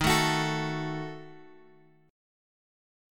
D#dim chord {x 6 7 x 7 5} chord
Dsharp-Diminished-Dsharp-x,6,7,x,7,5.m4a